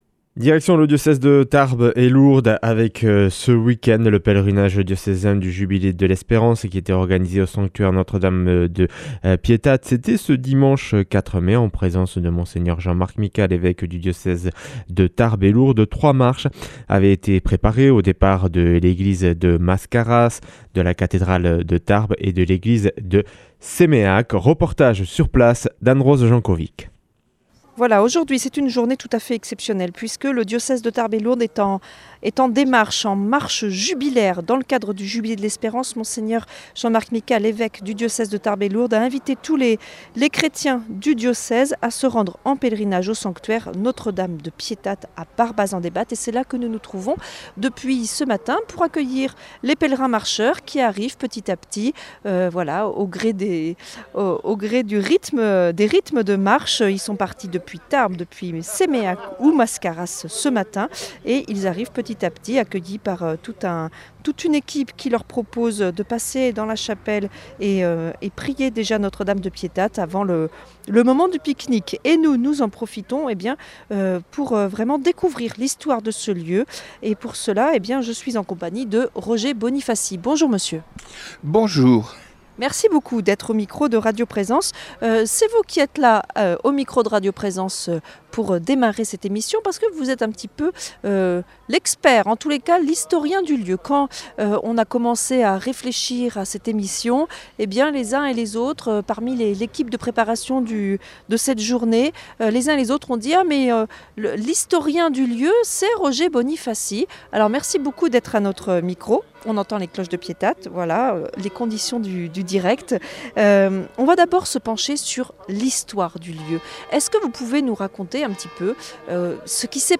Emission spéciale organisé au Sanctuaire Notre-Dame de Piétat commune de Barbazan-Debat pour le pèlerinage diocésain du Jubilé de l’Espérance, dimanche 4 mai 2025, en présence de Mgr Jean-Marc Micas, évêque de Tarbes et Lourdes. Trois marches avaient été préparées, aux départs de l’église de Mascaras, de la cathédrale de Tarbes et de l’église de Séméac.